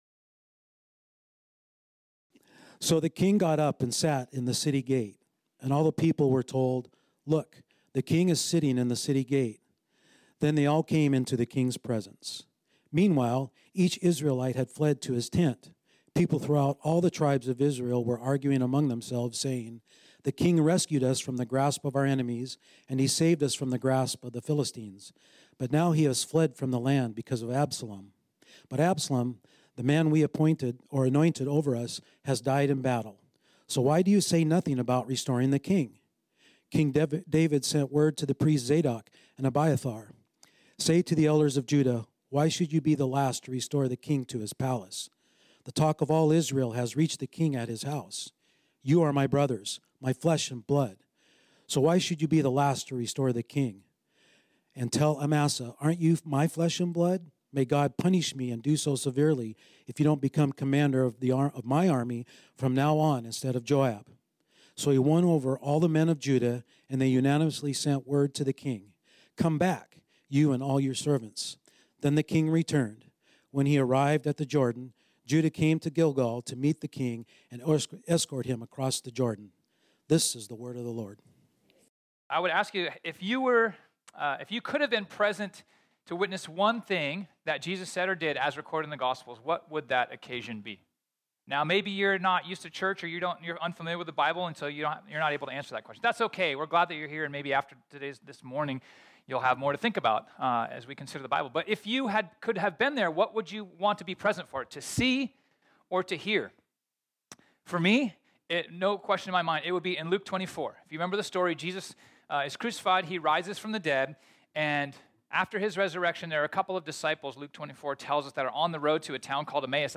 This sermon was originally preached on Sunday, August 20, 2023.